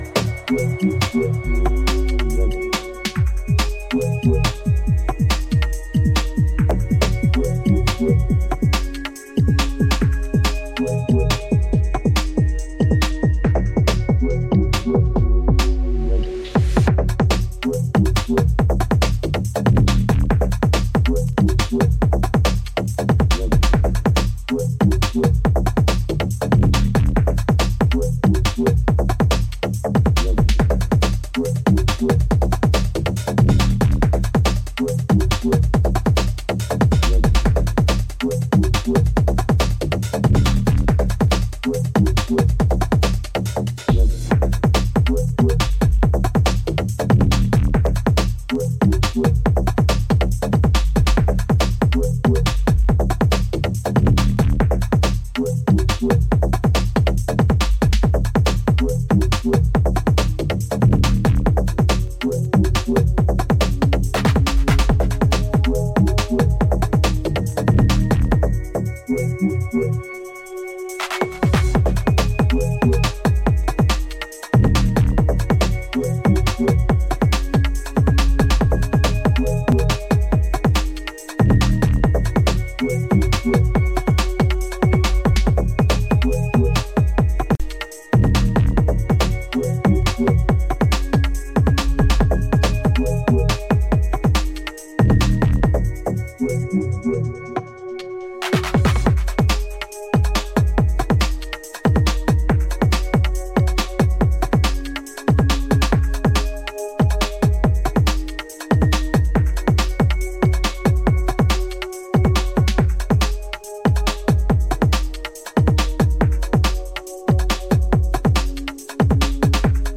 UK Garage / Breaks